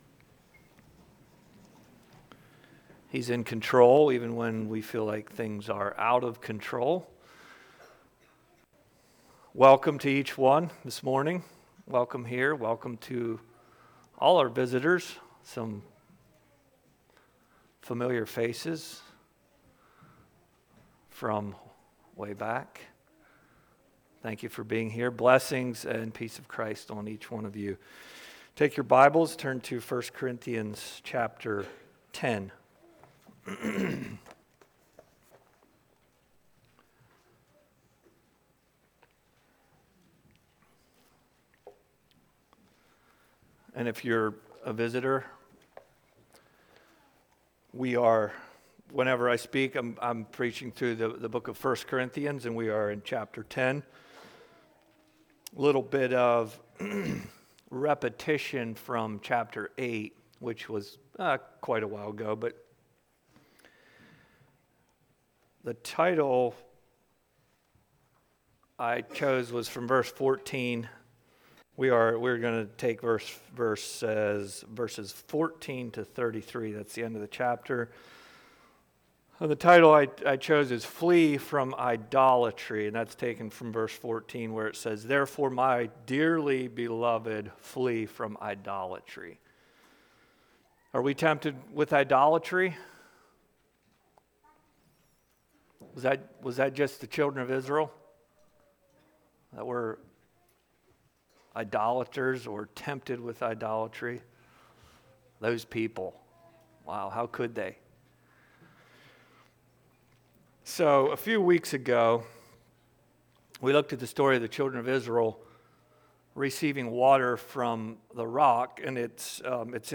Passage: I Corinthians 10:14-33 Service Type: Sunday Morning https